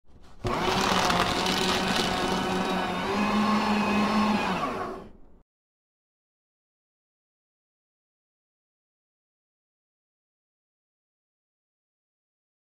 Paper Shredder
Office Appliances
Paper-Shredder-Free-Sound-effect-SFX-1.mp3